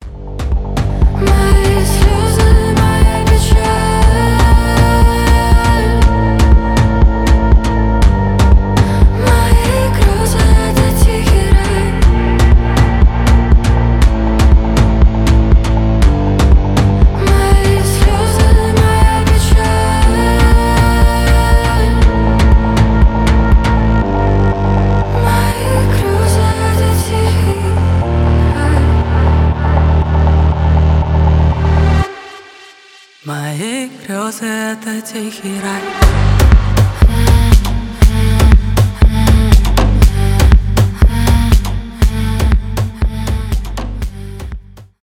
Категория: Танцевальные рингтоныРусские рингтоны